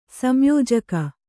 ♪ samyōjaka